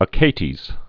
(ə-kātēz)